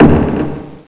hammer.wav